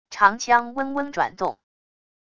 长枪嗡嗡转动wav音频